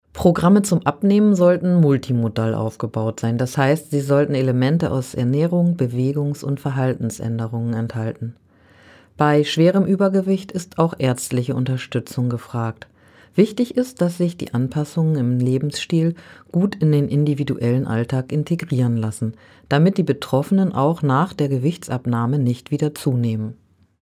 O-Töne20.08.2025